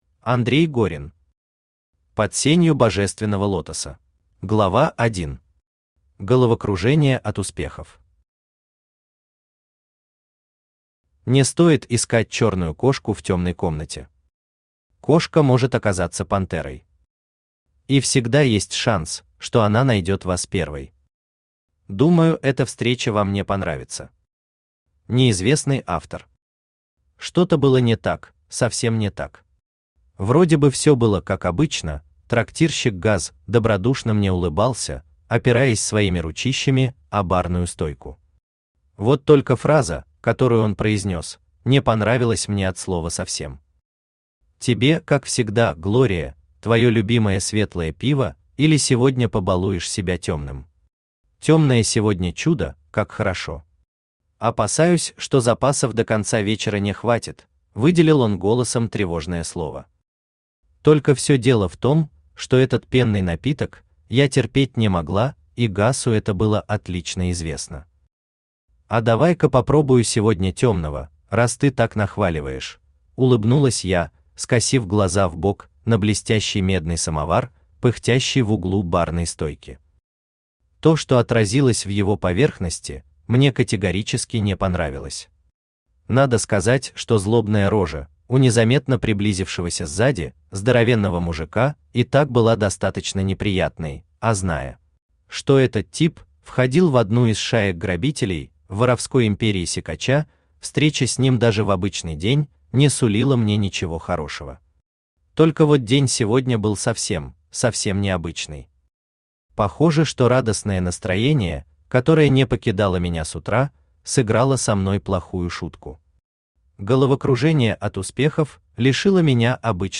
Aудиокнига Под сенью божественного лотоса Автор Андрей Горин Читает аудиокнигу Авточтец ЛитРес.